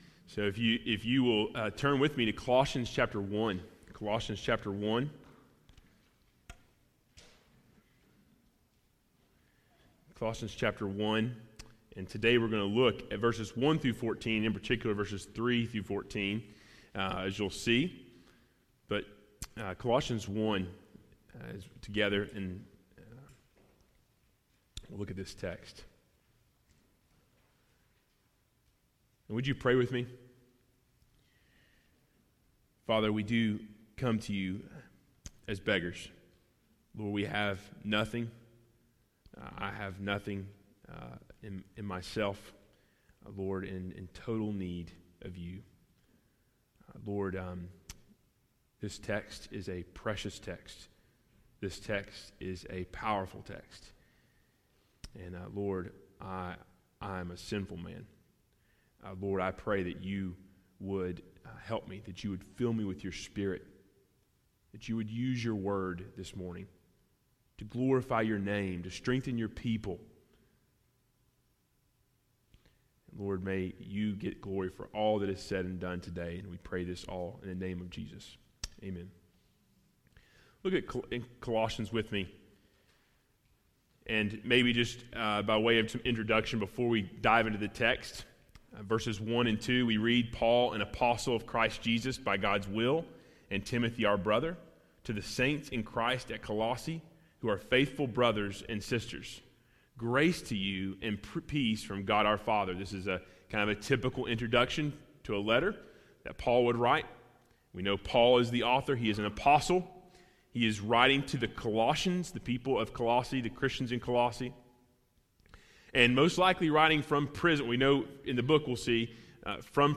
Sermon Audio 2018 December 30